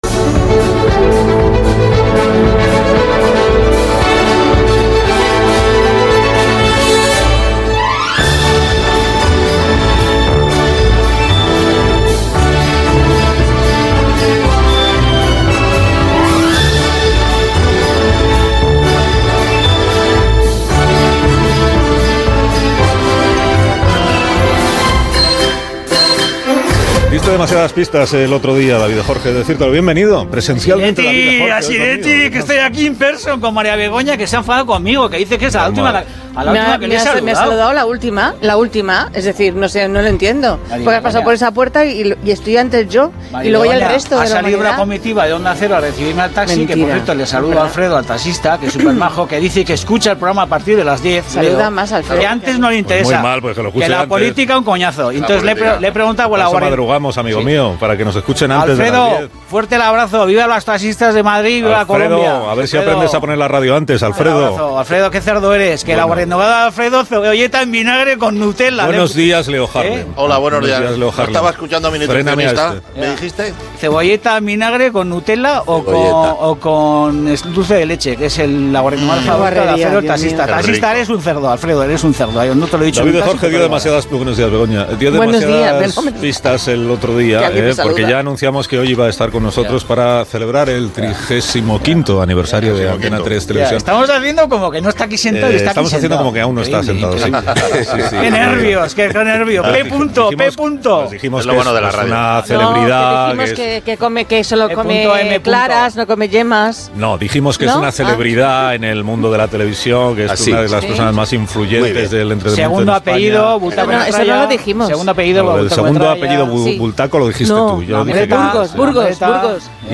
fc4dc86325f5ae18a871bdbc7bbd91e96a69f8f9.mp3 Títol Onda Cero Radio Emissora Onda Cero Barcelona Cadena Onda Cero Radio Titularitat Privada estatal Nom programa Más de uno Descripció Diàleg dels col·laboradors, entrevista a Pablo Moto. S'hi parla de la seva feina a la ràdio i la televisió, del programa "El hormiguero", perquè no dona entrevistes, perquè va deixar la ràdio...Indicatiu del programa i publicitat